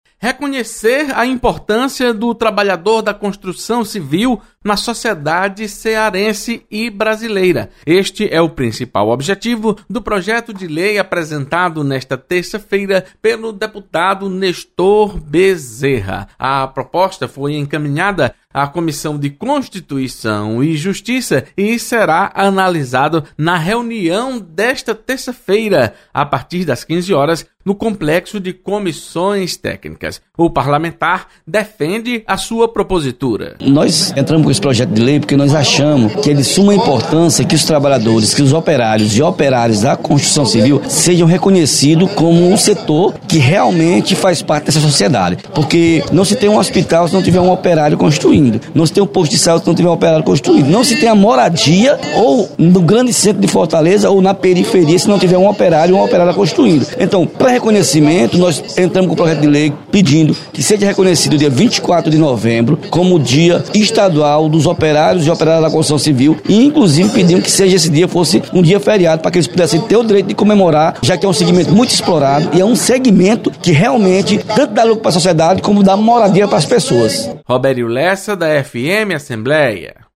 Deputado Nestor Bezerra apresenta projeto para criar o Dia do Trabalhador da Construção Civil no Ceará. Repórter